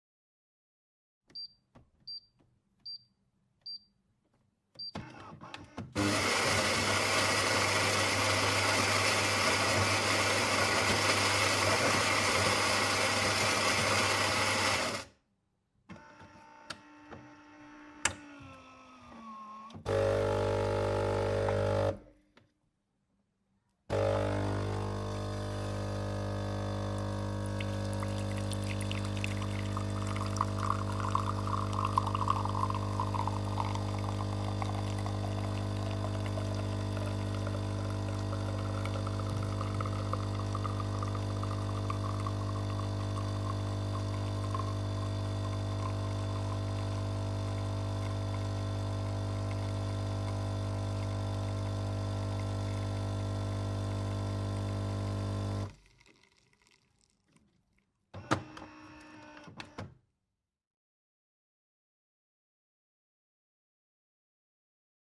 Tiếng Máy pha Cà phê, Cafe, Coffee… hoạt động
Thể loại: Tiếng ăn uống
Description: Hiệu ứng âm thanh của máy pha cà phê hơi nước mang đến tiếng rít đặc trưng, xen lẫn âm thanh xì hơi và tiếng nước sôi nhẹ nhàng, tạo cảm giác chân thực, sống động.
tieng-may-pha-ca-phe-cafe-coffee-hoat-dong-www_tiengdong_com.mp3